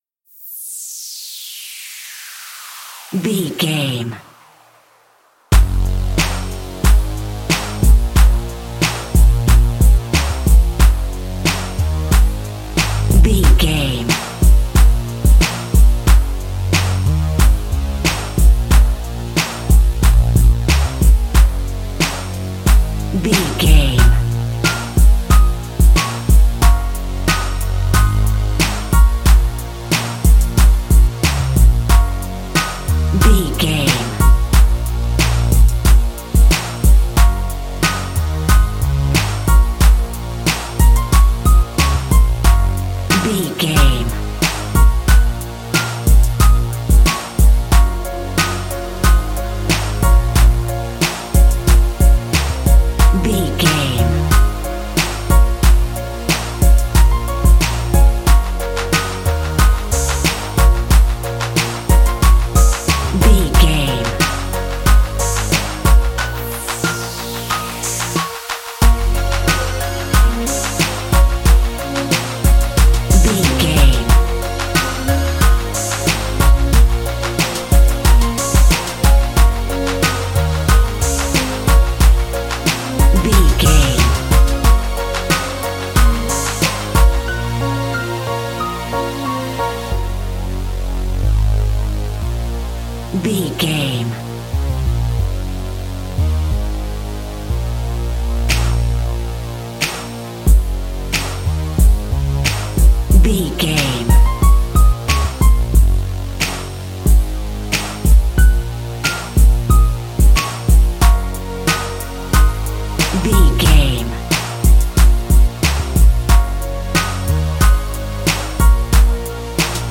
Aeolian/Minor
B♭
drum machine
synthesiser
percussion
Funk